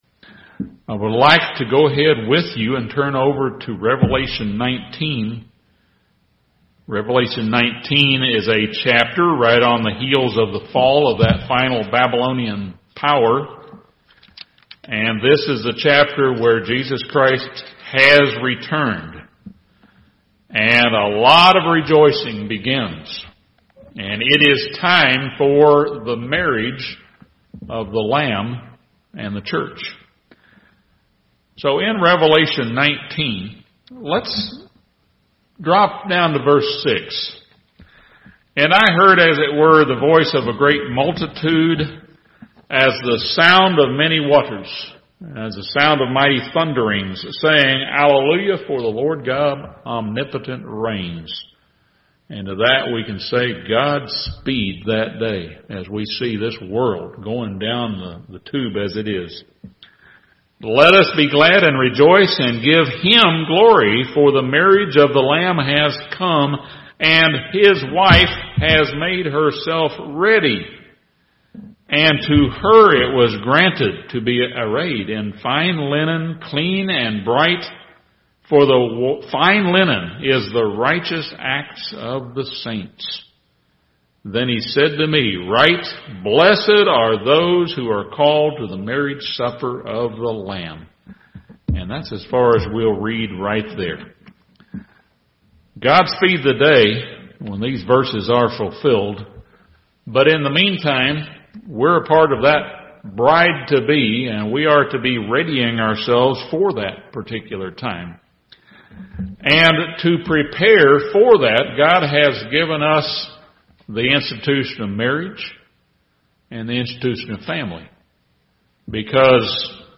This sermon discusses each partner's needs for companionship, respect, encouragement, love and a good example.